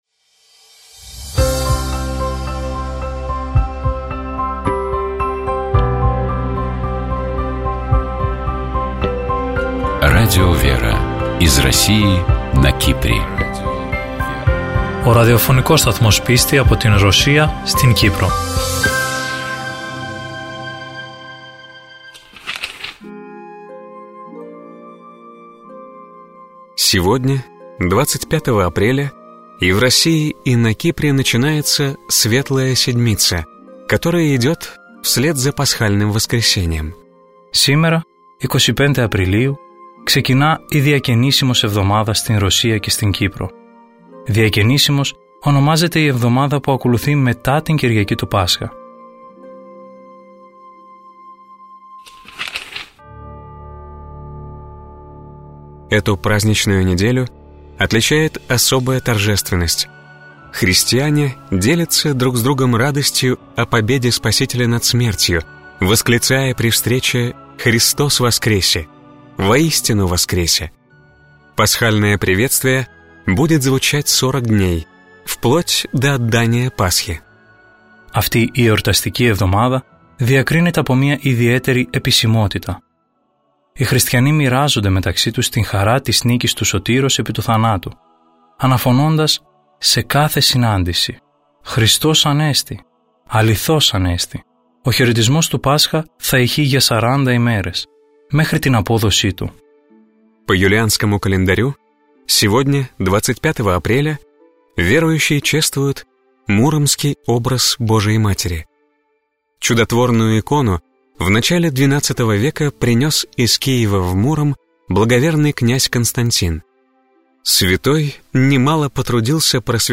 В эфире Радио ВЕРА программа «Философские ночи»